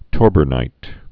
(tôrbər-nīt)